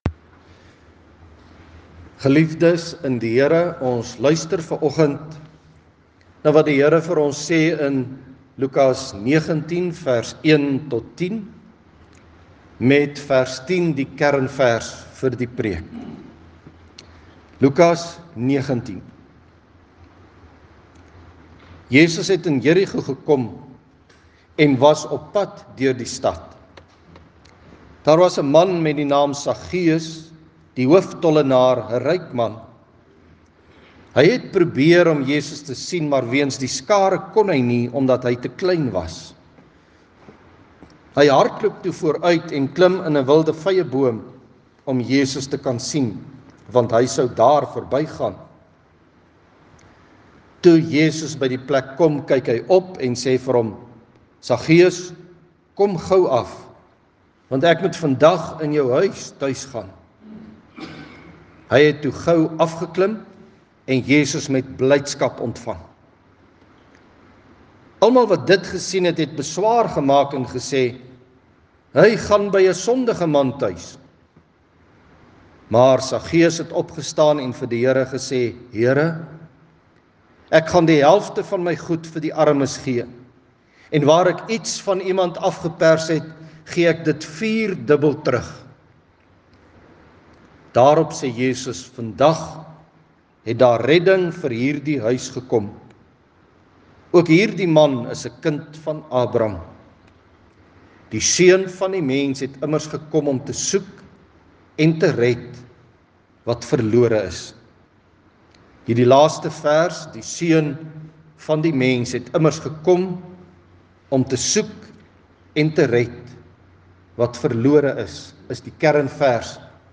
Vanoggend se erediens is juis ‘n voorbereidingsdiens.